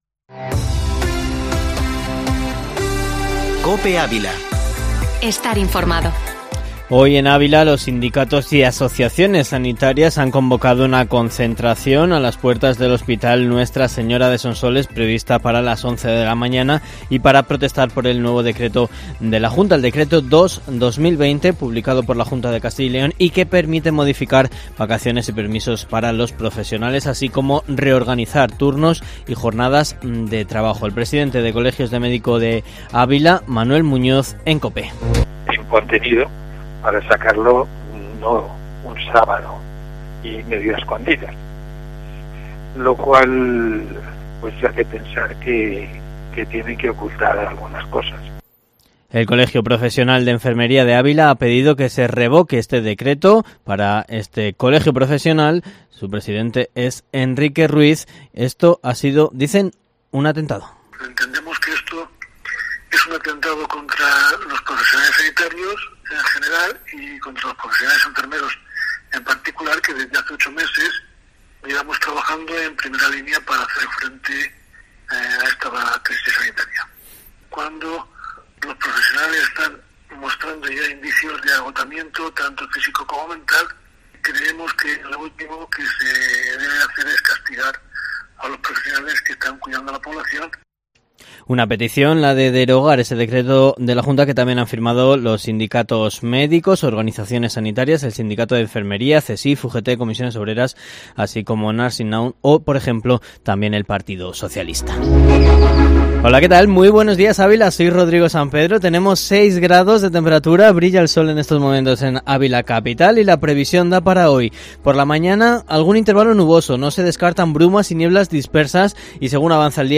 Informativo matinal Herrera en COPE Ávila 19/11/2020